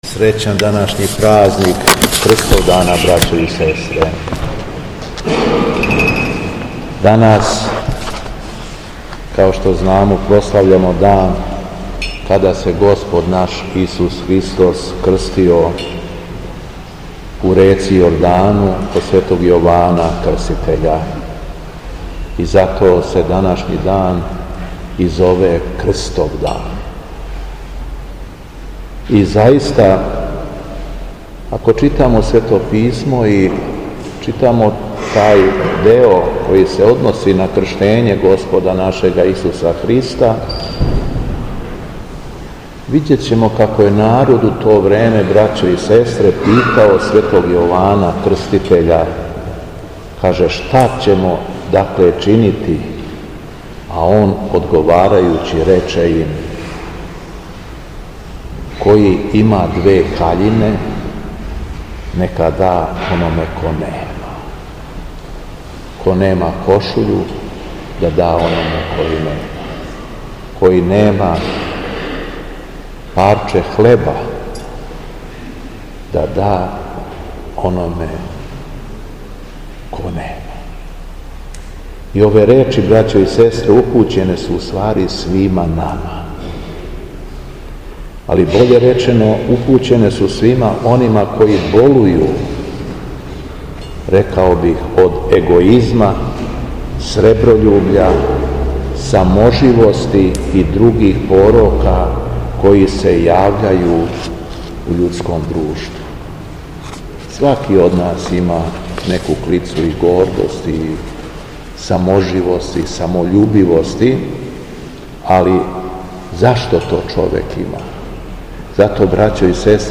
СВЕТА ЛИТУРГИЈА У САБОРНОМ ХРАМУ У КРАГУЈЕВЦУ НА КРСТОВДАН - Епархија Шумадијска
Беседа Његовог Високопреосвештенства Митрополита шумадијског г. Јована
Након прочитаног јеванђељског зачала, Митрополит Јован се свештенству и верном народу обратио беседом: